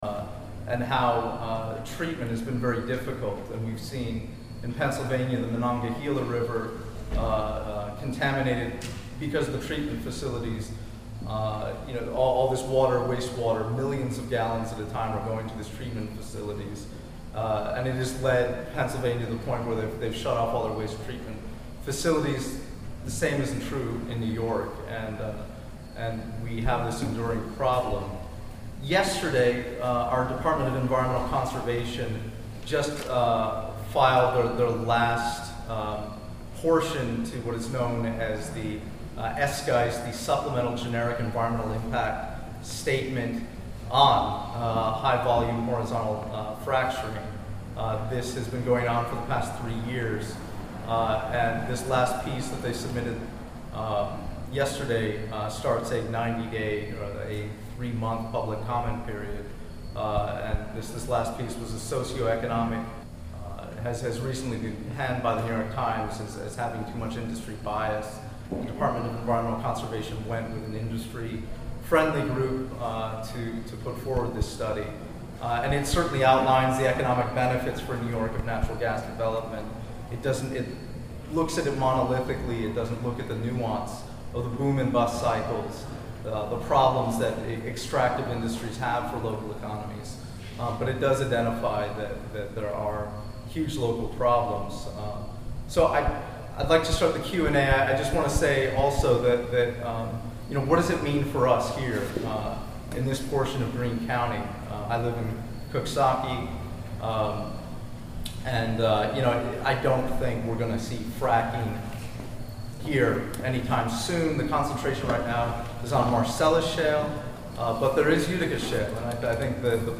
Crossroads Brewery